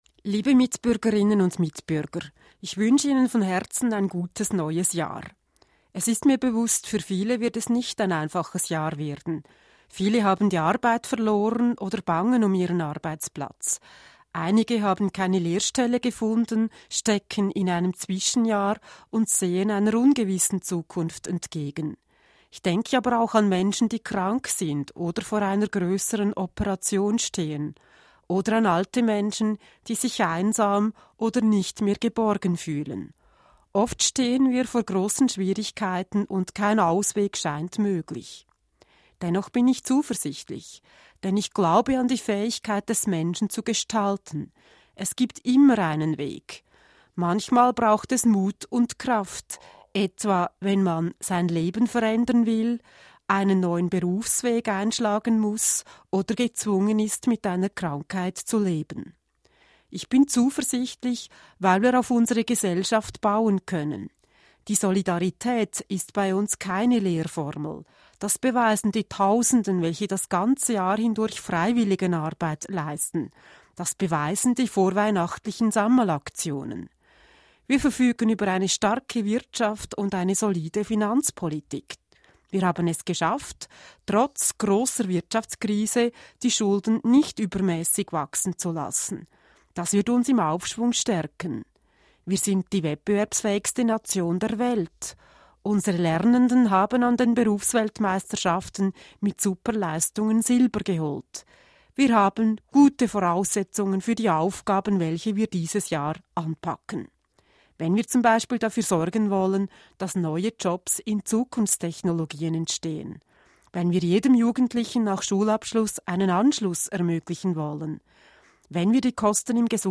Offizielle Neujahrsansprache von Bundespräsidentin Doris Leuthard